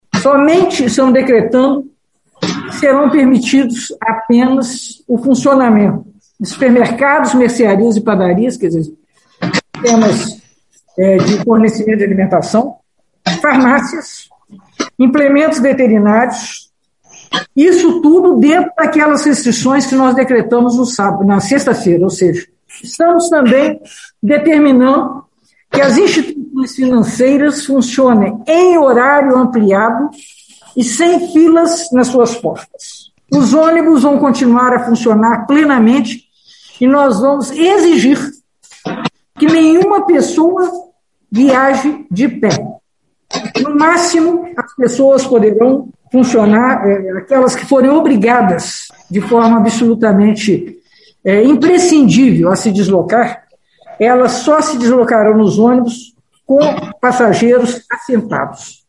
O anúncio foi feito pela prefeita Margarida Salomão (PT) em coletiva de imprensa na manhã deste domingo, 7.
prefeita Margarida Salomão